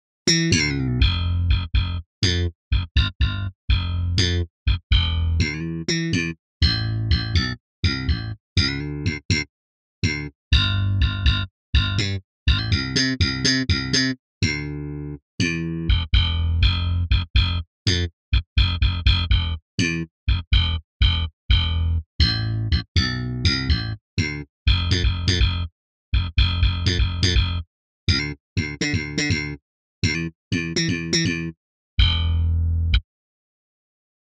Slap Ambient